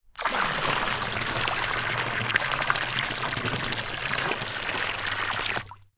Water_59.wav